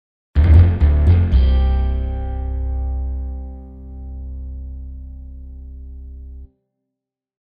Stingers
Bass and Guitar.mp3